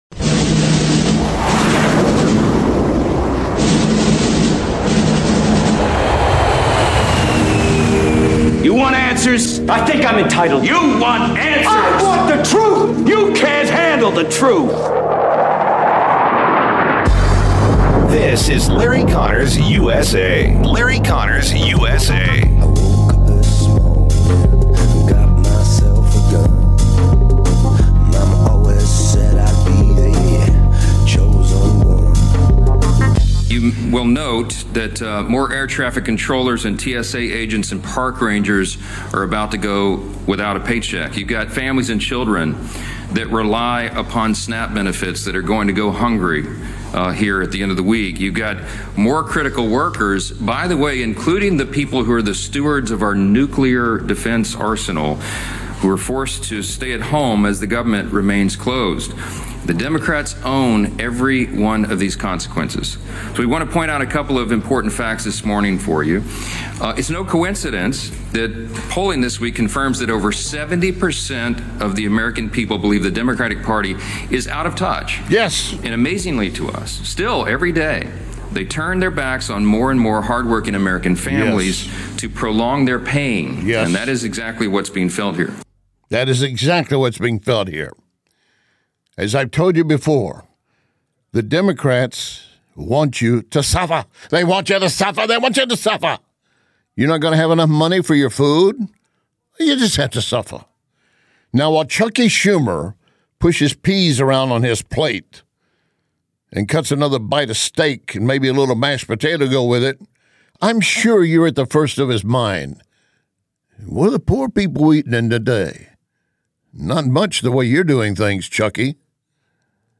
Guest: Gordon Chang RUMBLE